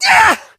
Media:edgar ulti vo 05.ogg Edgar screams
艾德加尖叫